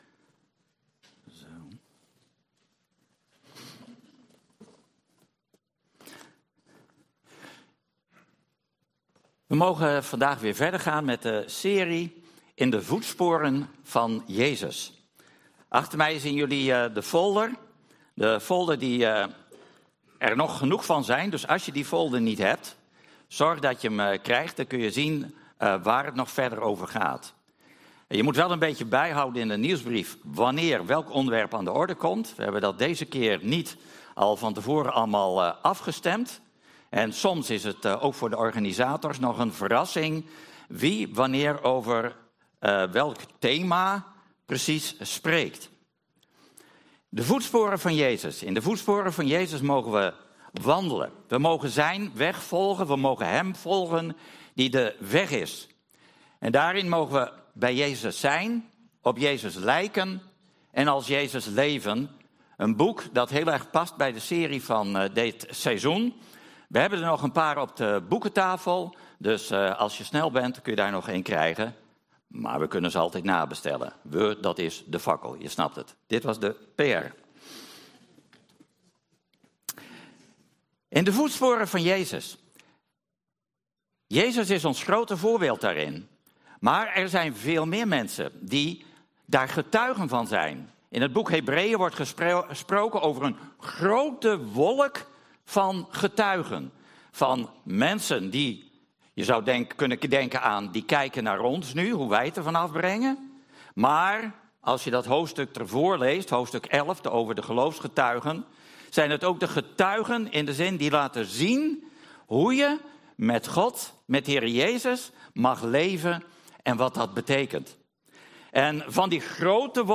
Toespraak 24 november: ontferming over je vijand - De Bron Eindhoven